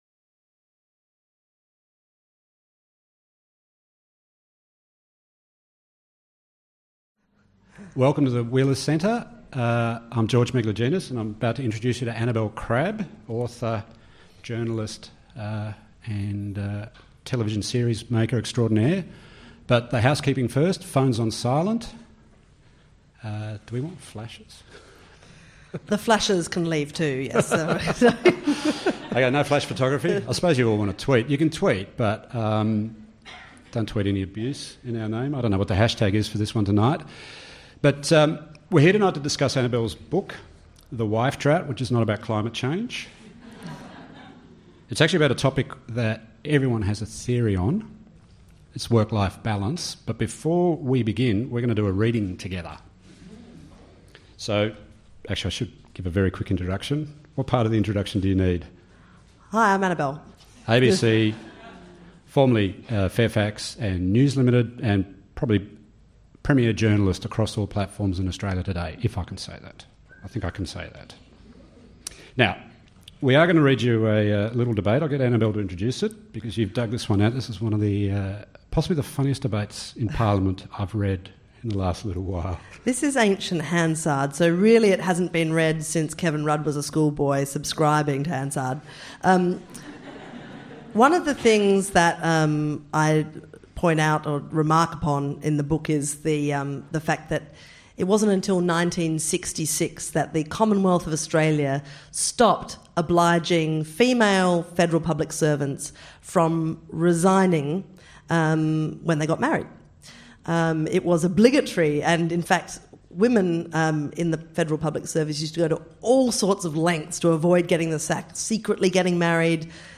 In The Wife Drought, author and political correspondent Annabel Crabb calls for a ceasefire in the gender wars – and a genuine conversation about the personal, policy and workplace changes necessary for real equality. She’ll be in conversation with George Megalogenis.